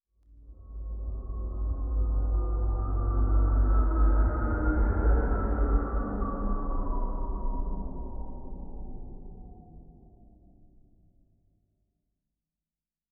pgs/Assets/Audio/Sci-Fi Sounds/Movement/Distant Ship Pass By 1_3.wav at master
Distant Ship Pass By 1_3.wav